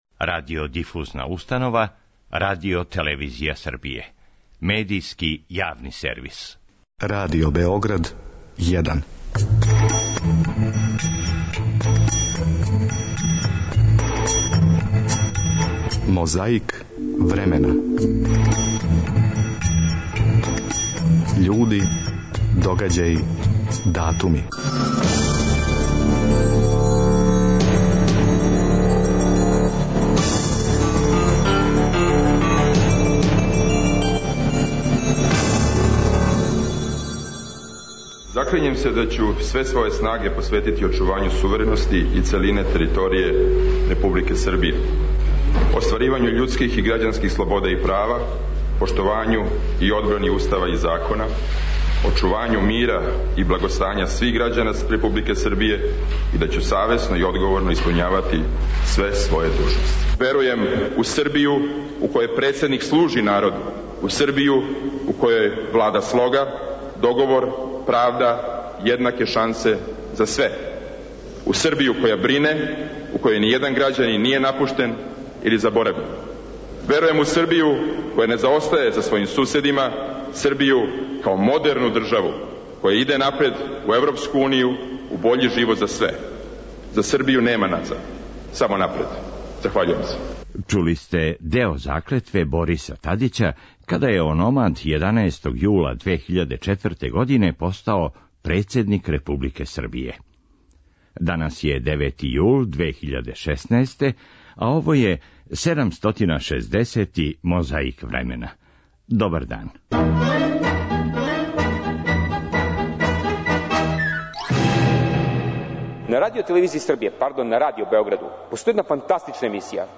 У овонедељној борби против пилећег памћења подсећамо вас како је говорио народни херој Блажо Јовановић присећајући се 13. јула 1941. године, дана када је Црна Гора започела борбу против окупатора.
Седницу је отворио Миломир Минић.
Овако је извештавао Радио Београд.